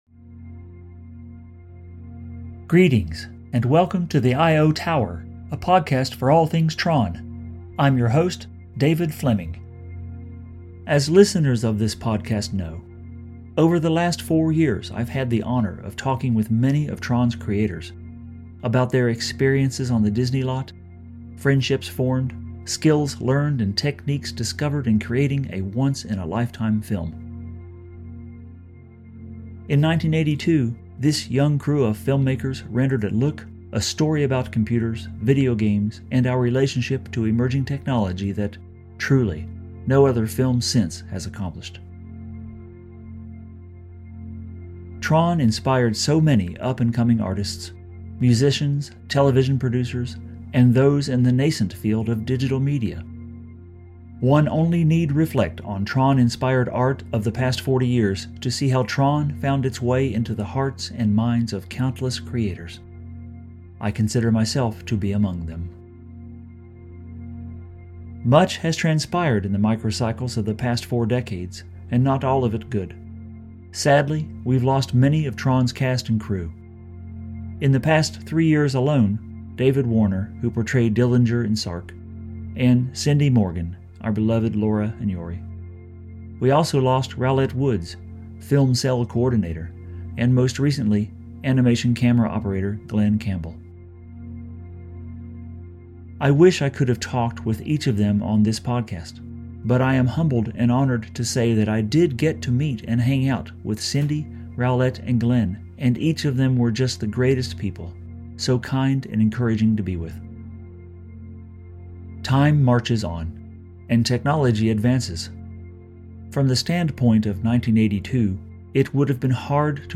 TRON director Steven Lisberger joins me on The I/O Tower!